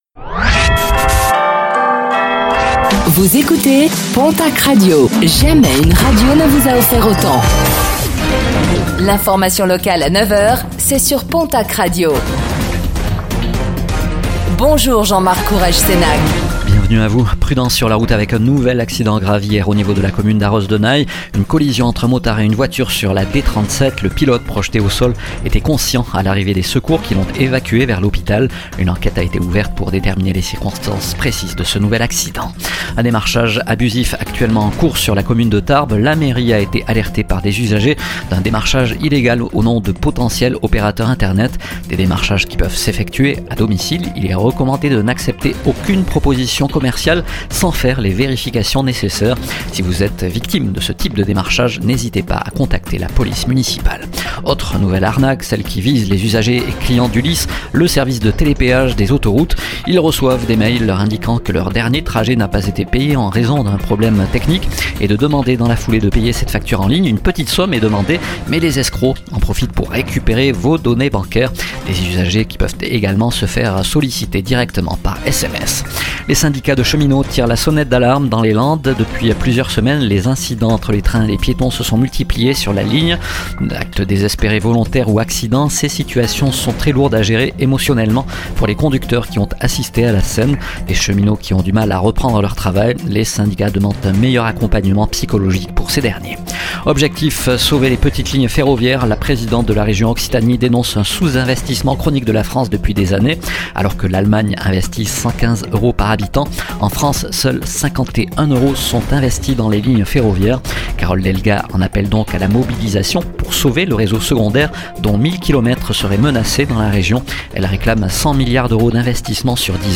Réécoutez le flash d'information locale de ce vendredi 13 juin 2025